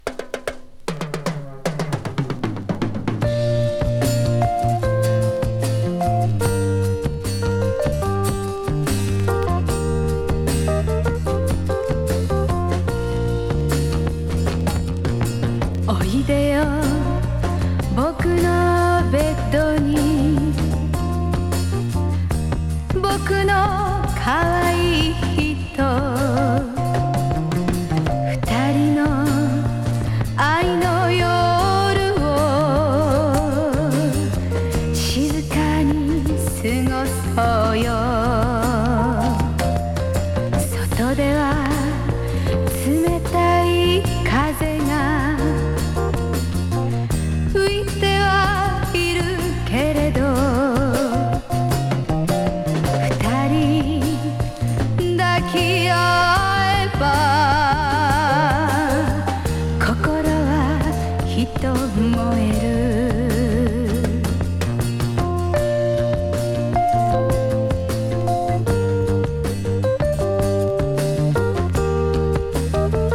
ソフトロックアレンジが◎。